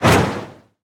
Minecraft Version Minecraft Version snapshot Latest Release | Latest Snapshot snapshot / assets / minecraft / sounds / mob / breeze / shoot.ogg Compare With Compare With Latest Release | Latest Snapshot
shoot.ogg